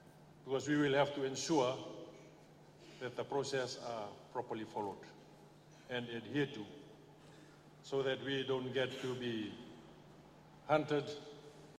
Aseri Radrodro speaking during the Head Teachers Conference